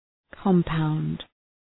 Προφορά
{kəm’paʋnd}